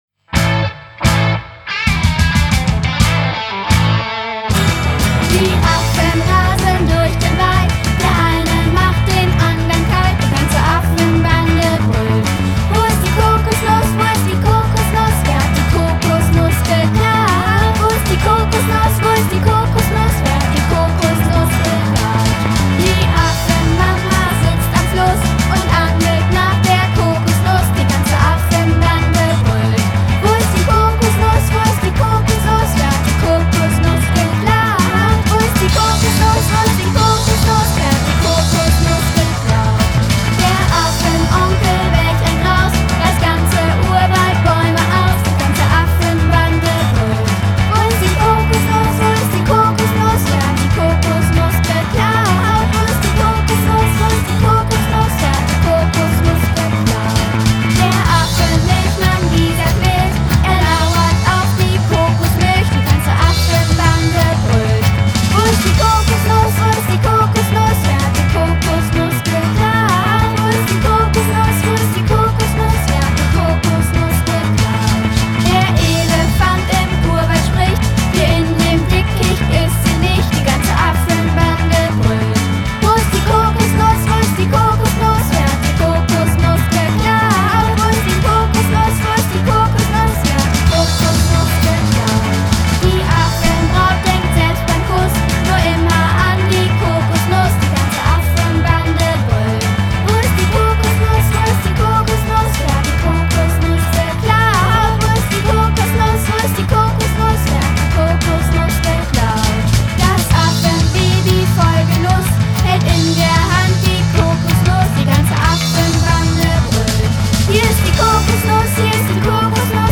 Kinderparty